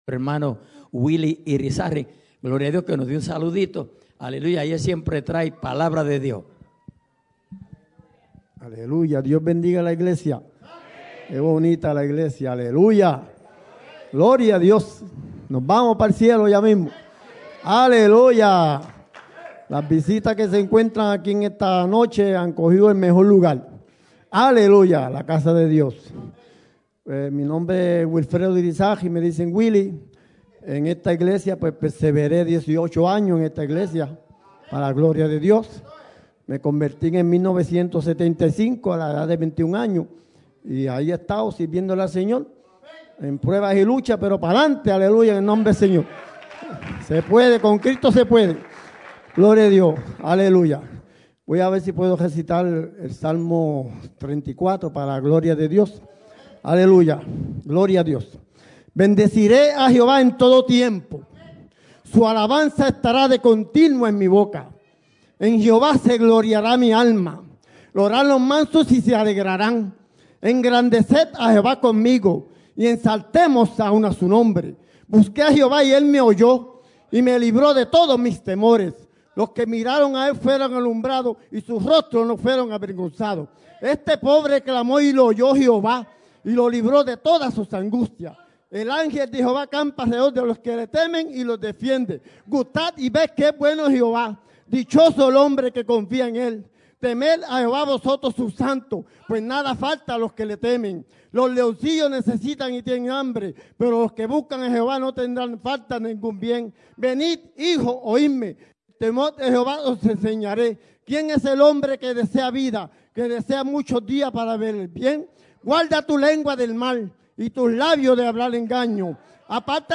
Servicio de Alabanza y Adoración